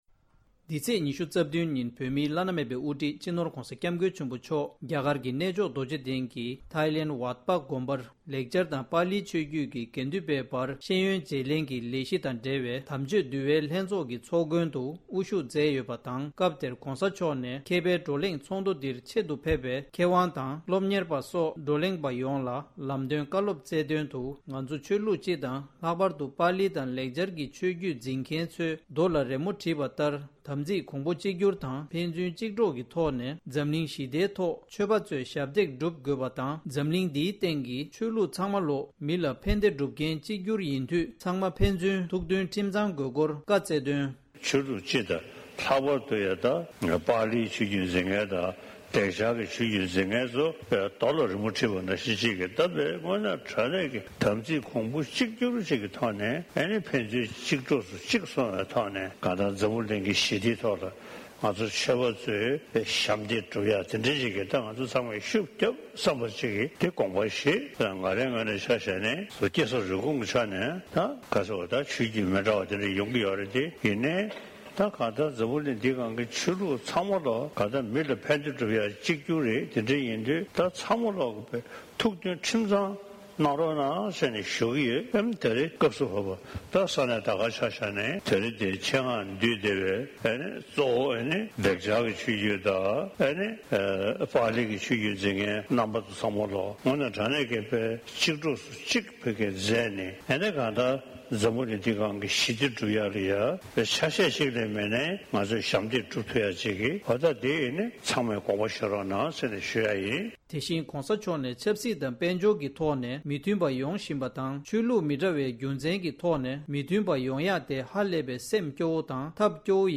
༸གོང་ས་མཆོག་གིས་འཛམ་གླིང་གི་ཆོས་ལུགས་ཚང་མ་ཕན་ཚུན་ཐུགས་མཐུན་ཁྲིམས་གཙང་དགོས་སྐོར་བཀའ་སྩལ་བ། ༸གོང་ས་མཆོག་རྡོ་རྗེ་གདན་དུ་ཝ་ཊ་པ་དགོན་པར་ཆིབས་སྒྱུར་སྐབས། ༢༠༢༢།༡༢།༢༧ ཉིན།
སྒྲ་ལྡན་གསར་འགྱུར།